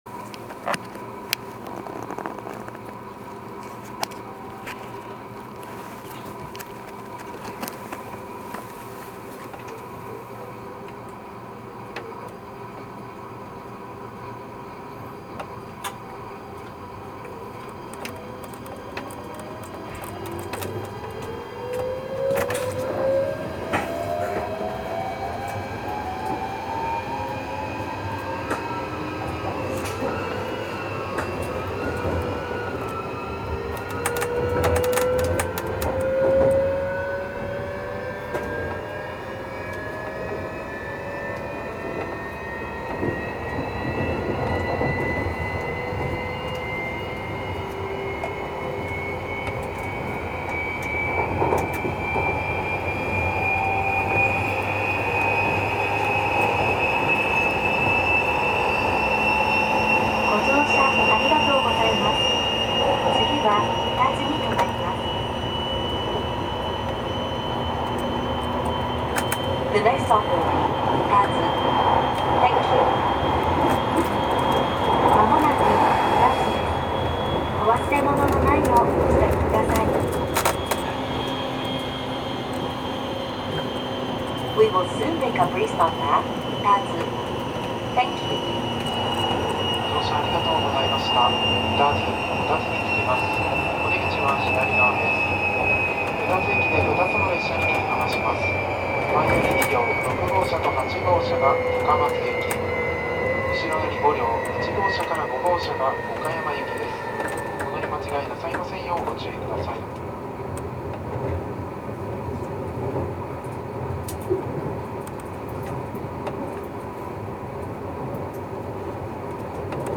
走行機器は2レベルIGBT素子によるVVVFインバータ制御で、定格220kWのS-MT63形三相かご形誘導モーターを制御します。
走行音
録音区間：丸亀～宇多津(しおかぜ20号・いしづち20号)(お持ち帰り)